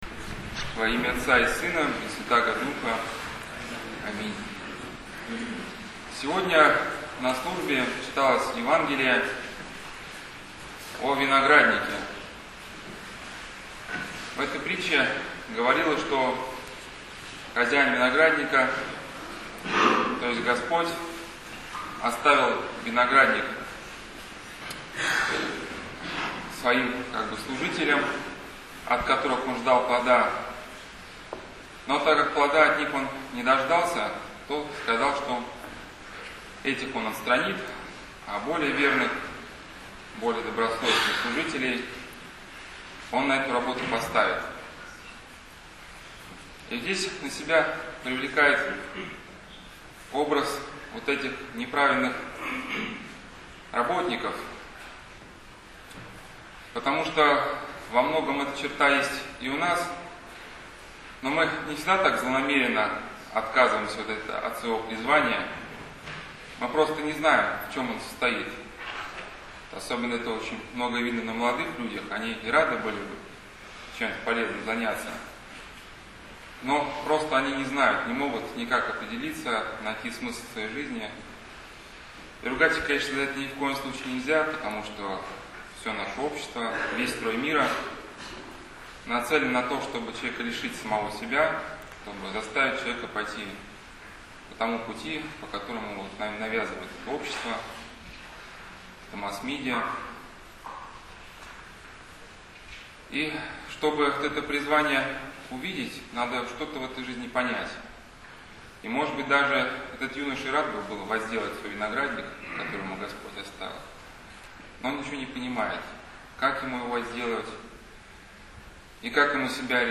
Тип: Проповеди